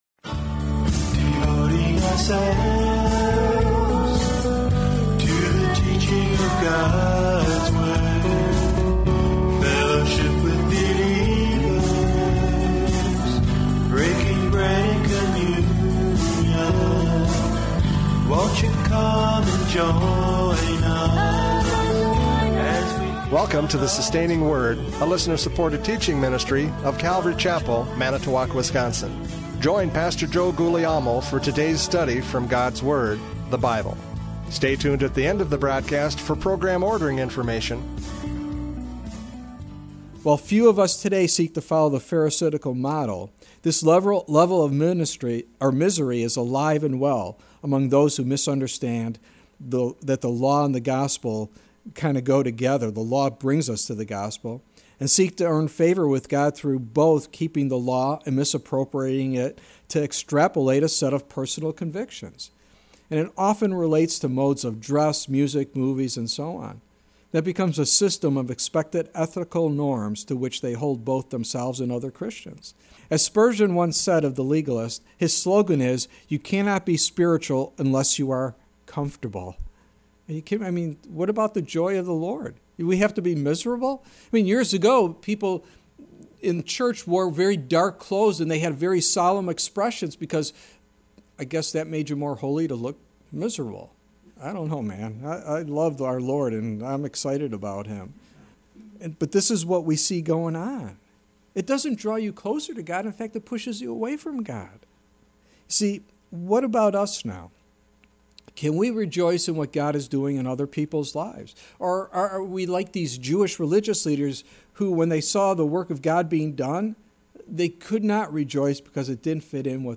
John 5:10-16 Service Type: Radio Programs « John 5:10-16 Legalism Kills!